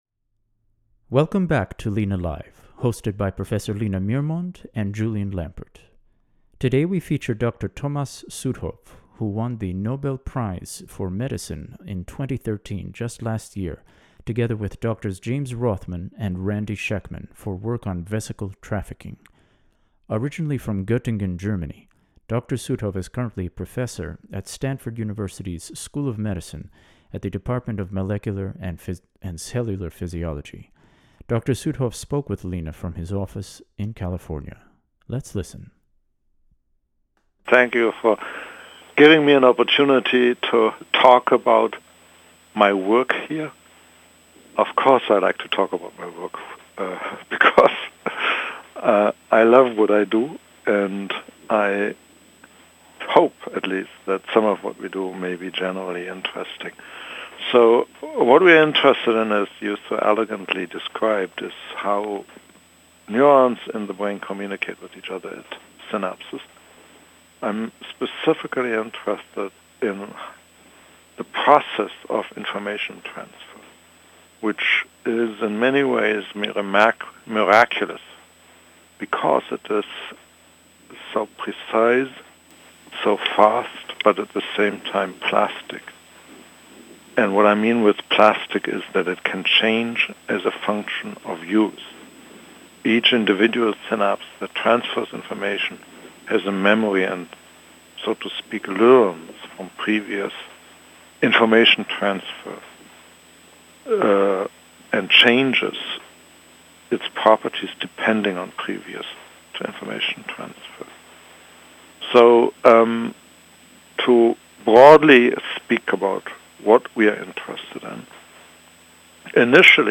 Talk Show Episode
Guests, Prof. Dr. Thomas C. Südhof, Dr. James E. Rothman and Dr. Randy W. Schekman